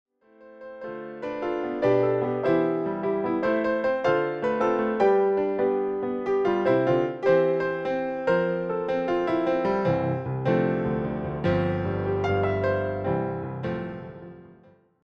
solo piano takes on Broadway material